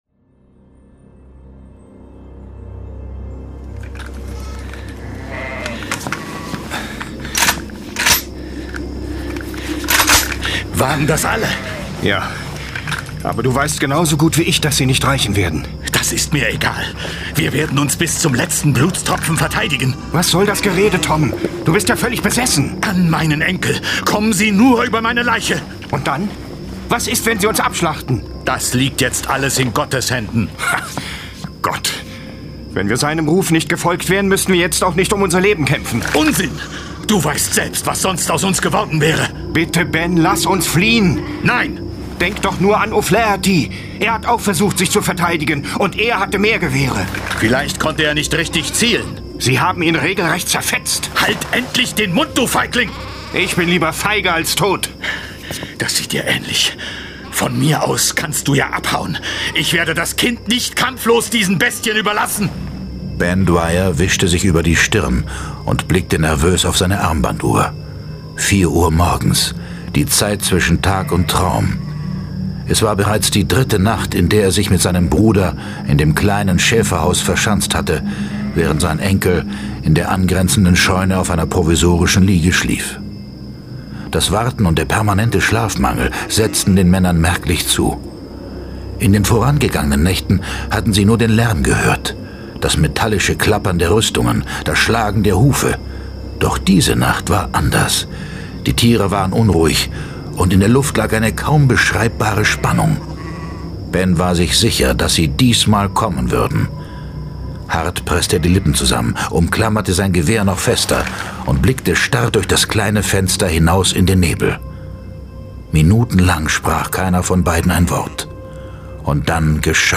Hörspiel Jason Dark